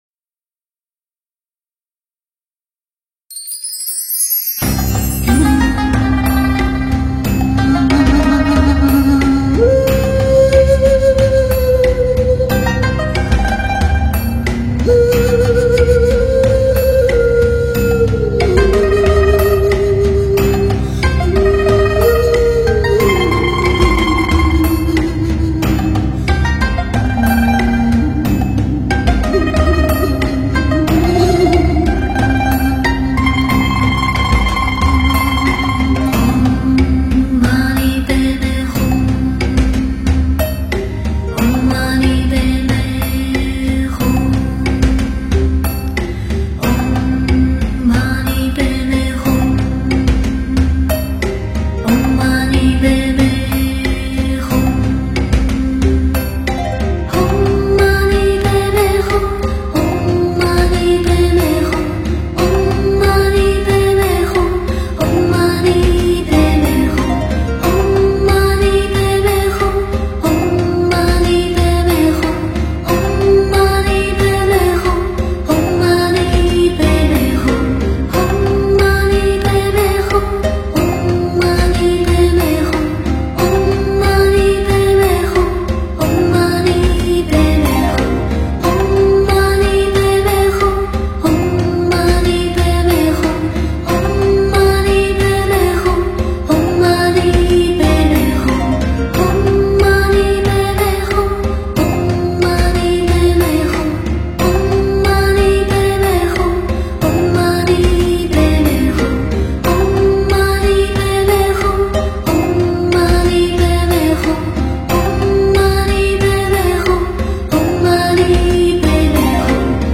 佛音 诵经 佛教音乐 返回列表 上一篇： 半若波罗蜜多心经 下一篇： 吉祥飞扬(准提神咒